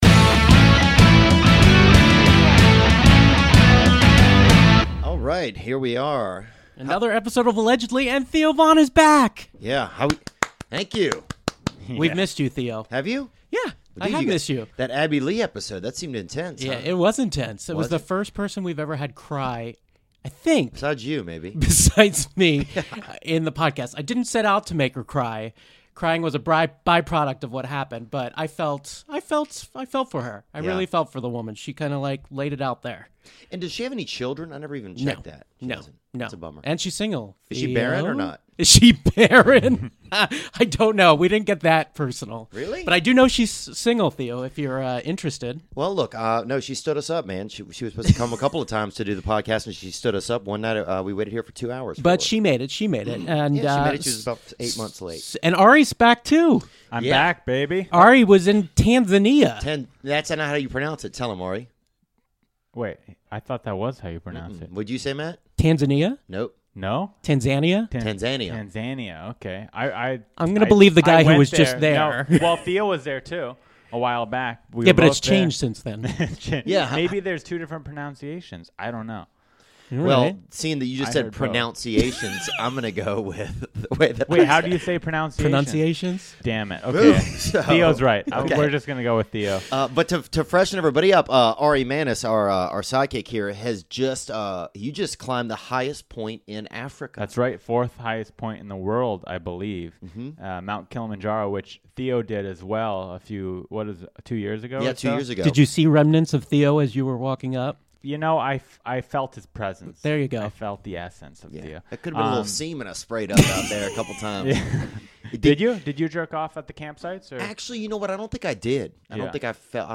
The Rob Lowe Roastmaster DAVID SPADE is in studio, talking all the dirt behind the Roast. He talks Anne Coulter, Joe Dirt, Joe Dirt 2, and muchas mas.